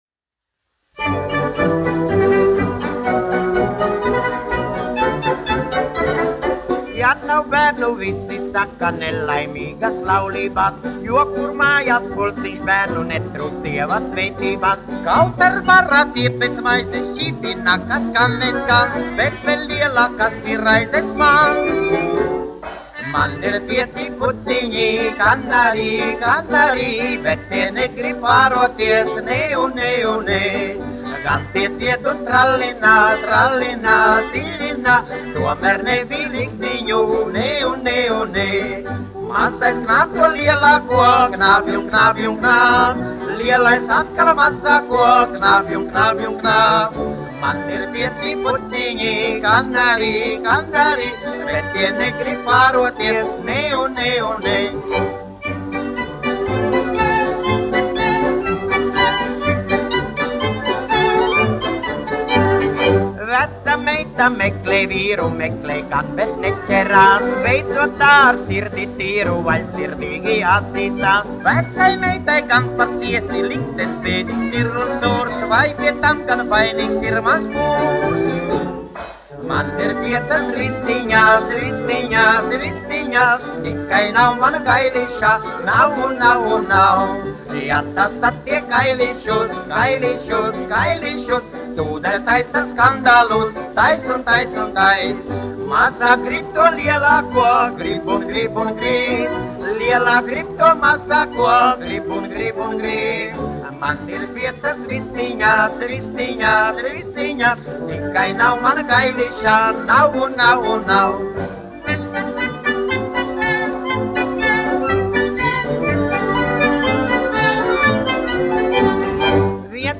1 skpl. : analogs, 78 apgr/min, mono ; 25 cm
Fokstroti
Populārā mūzika -- Latvija